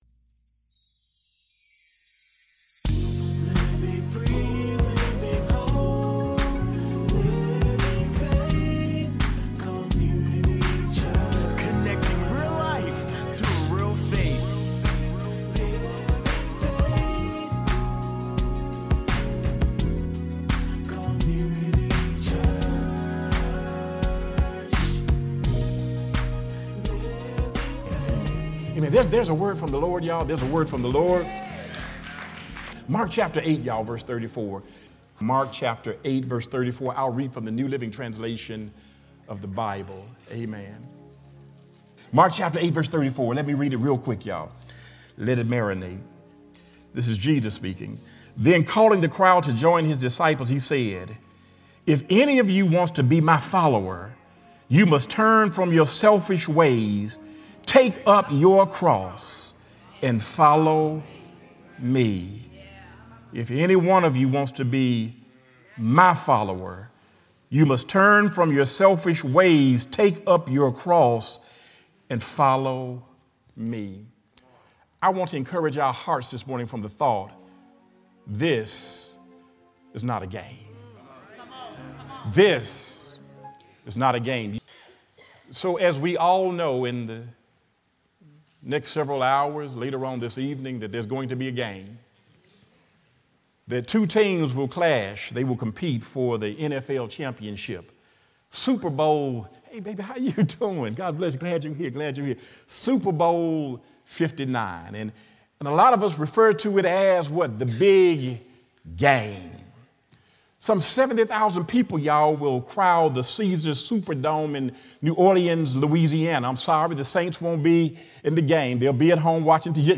Sermons | Living Faith Community Church